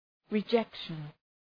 Προφορά
{rı’dʒekʃən}